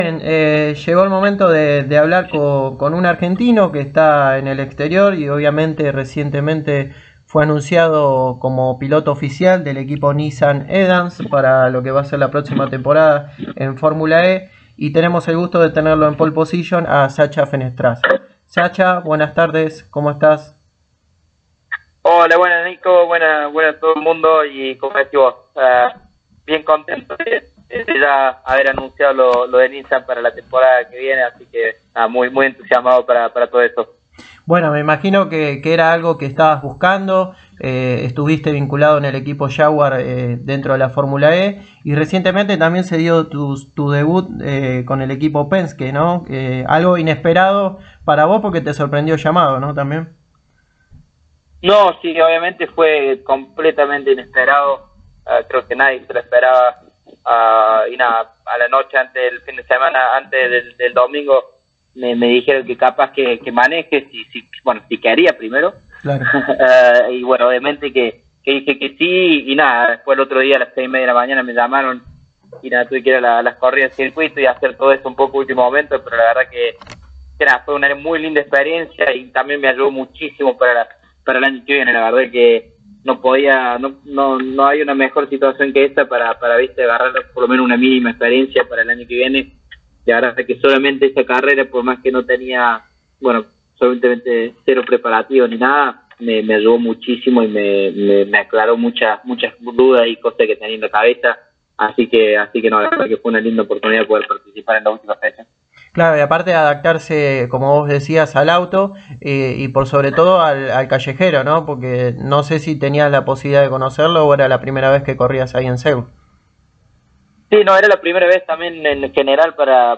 A continuación, la nota con Sacha Fenestraz: